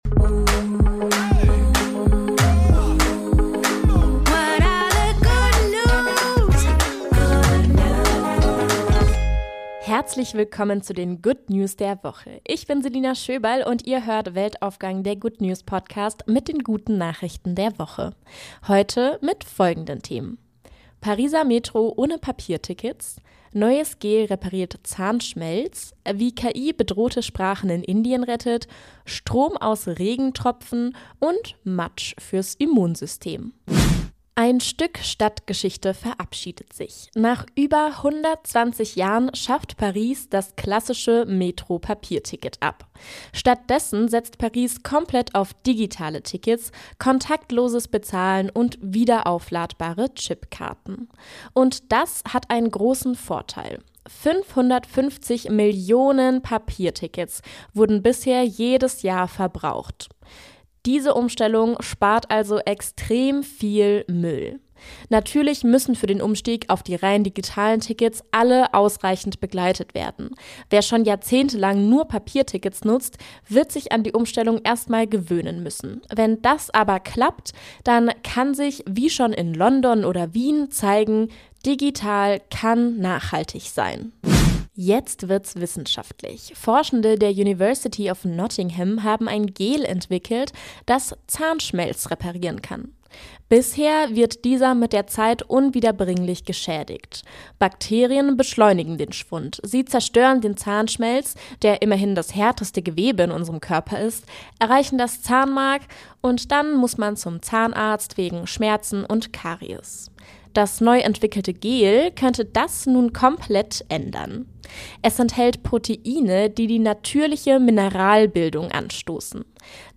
AUFNAHMELEITUNG UND MODERATION